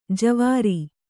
♪ javāri